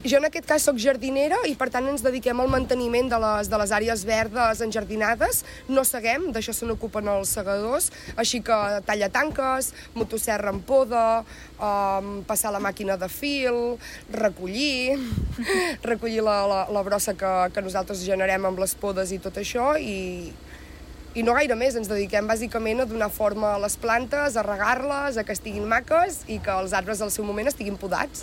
Una de les treballadores de l’àrea de jardineria explica que a la brigada fan tasques molt diverses. “Com a jardiners ens encarreguem que les àrees verdes anjardinades estiguin en bones condicions”, admet la treballadora.